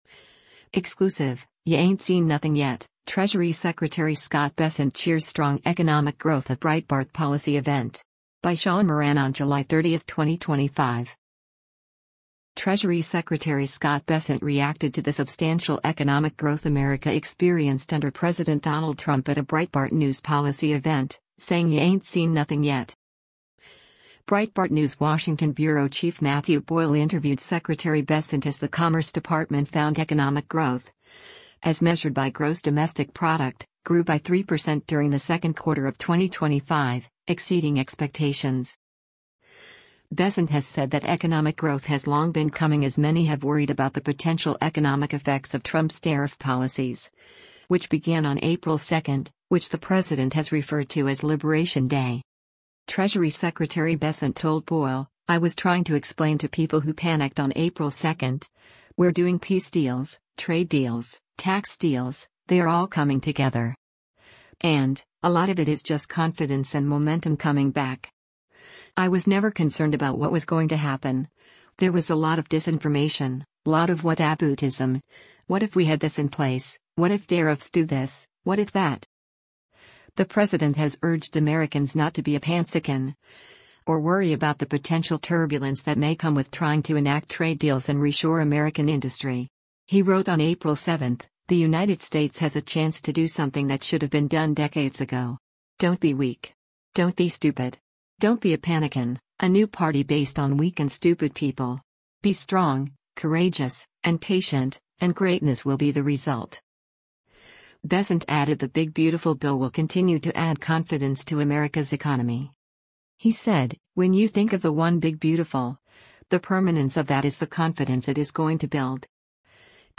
Exclusive—’Ya Ain’t Seen Nothing Yet’: Treasury Secretary Scott Bessent Cheers Strong Economic Growth at Breitbart Policy Event
Treasury Secretary Scott Bessent reacted to the substantial economic growth America experienced under President Donald Trump at a Breitbart News policy event, saying “ya ain’t seen nothing yet.”